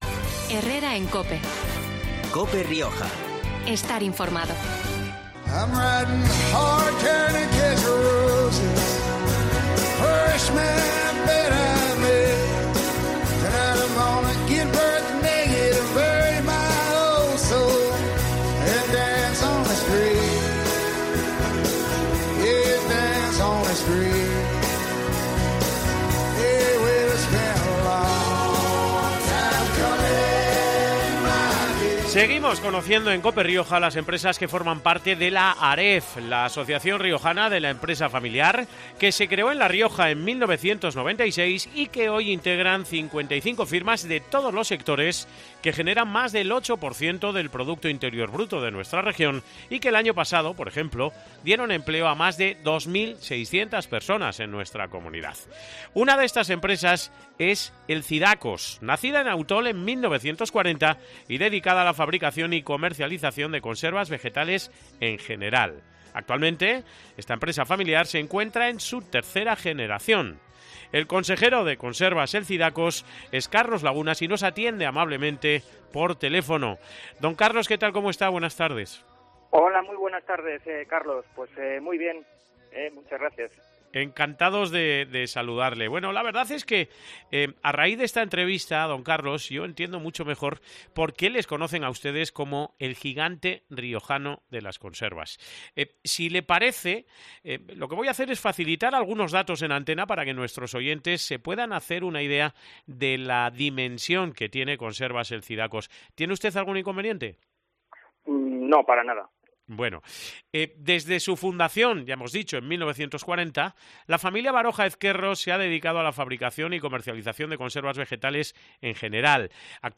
con él hemos hablado este mediodía en COPE Rioja